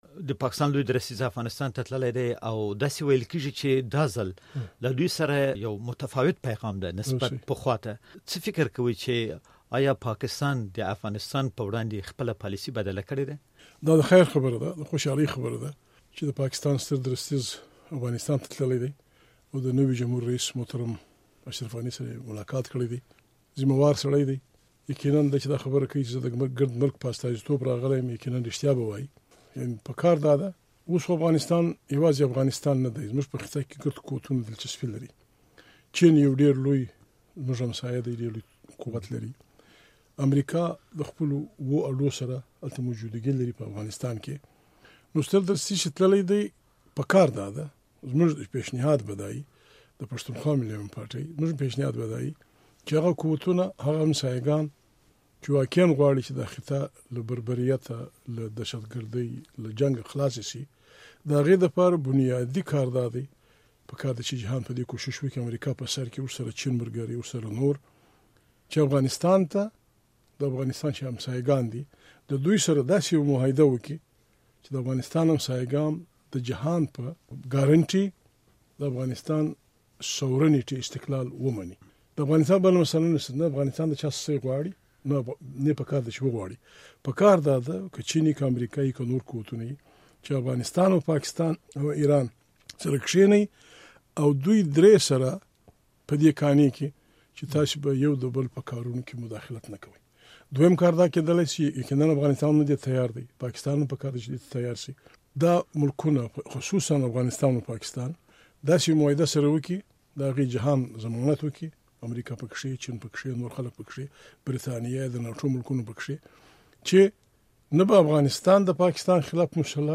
له محمود خان اڅکزي سره مرکه